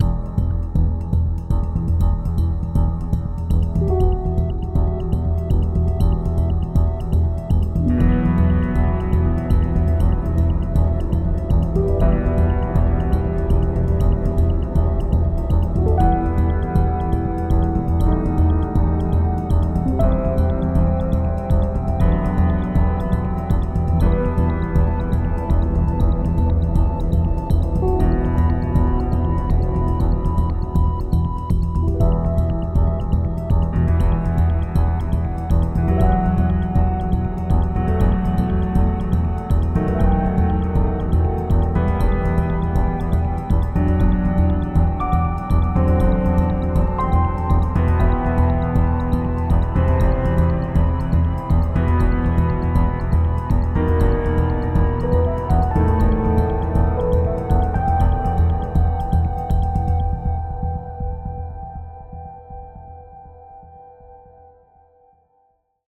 Added Ambient music pack. 2024-04-14 17:36:33 -04:00 18 MiB Raw History
Ambient Concern cut 60.wav